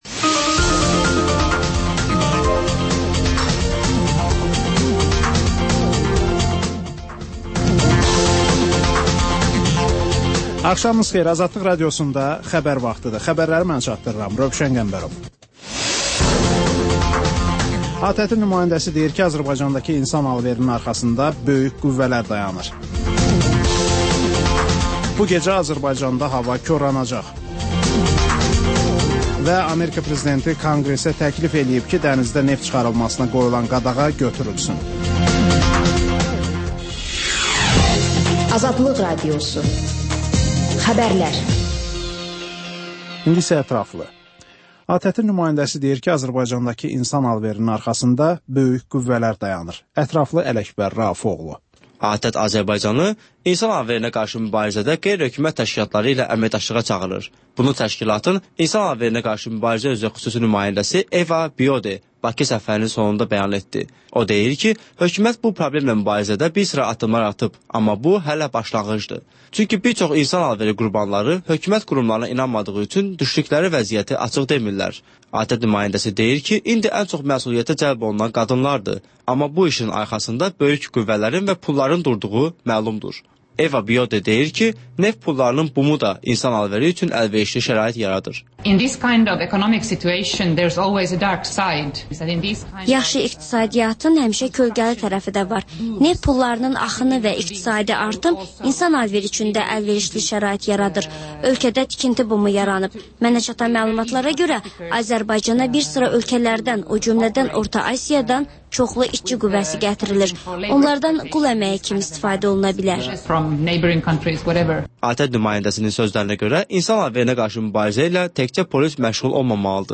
Xəbərlər, müsahibələr, hadisələrin müzakirəsi, təhlillər, sonda 14-24: Gənclər üçün xüsusi veriliş